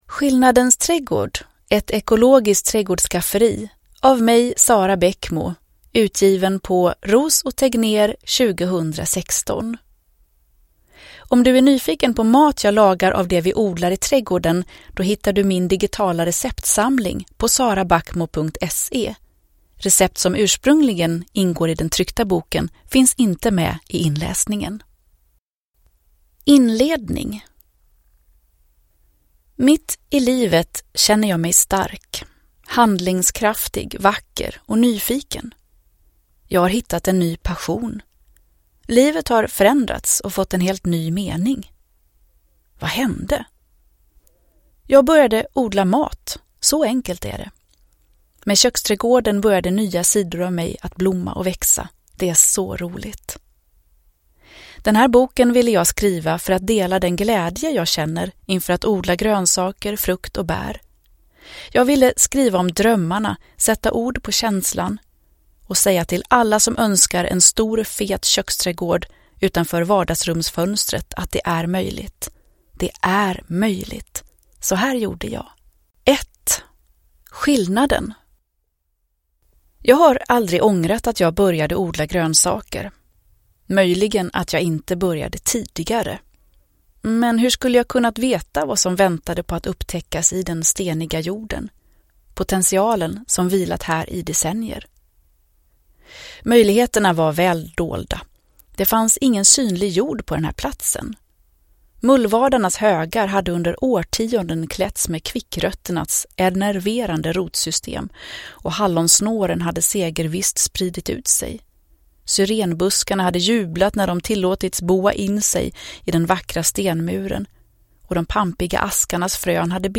Skillnadens Trädgård : Ett ekologiskt trädgårdsskafferi – Ljudbok – Laddas ner